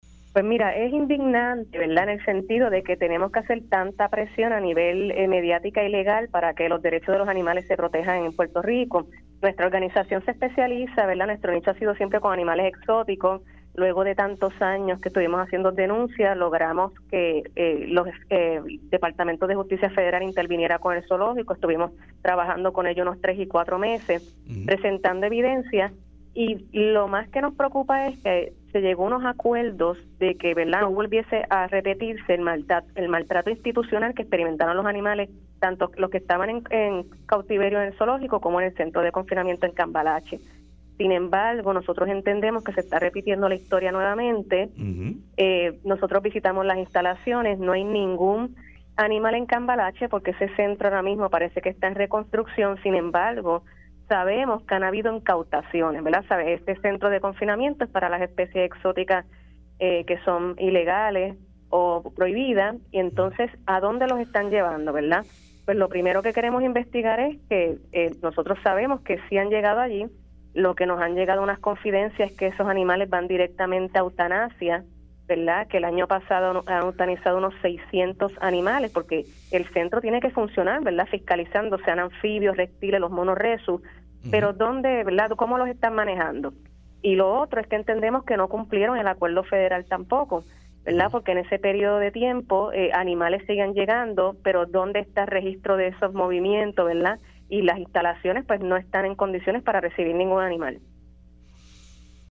entrevista para Dígame la Verdad